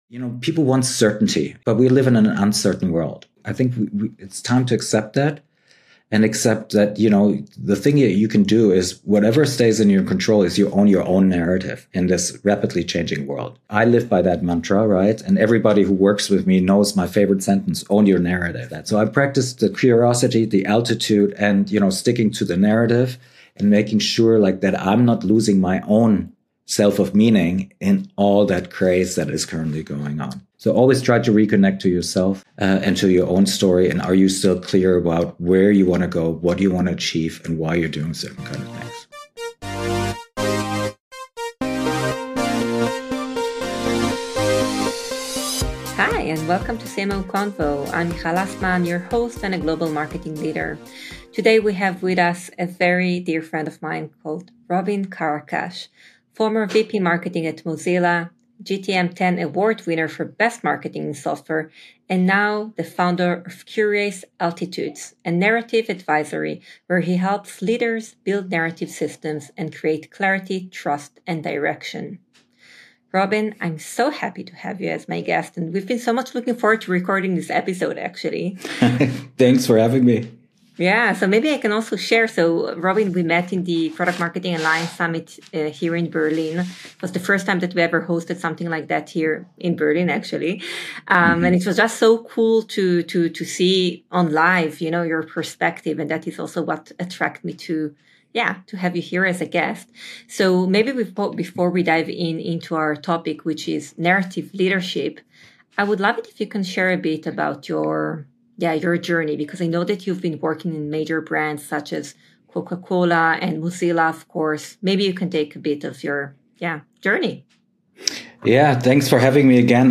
In each episode, we have in-depth conversations with CMOs and top-level marketers from around the world, across every industry and level of experience, in order to get their insights into what it takes to excel at the very top of the marketing hierarchy.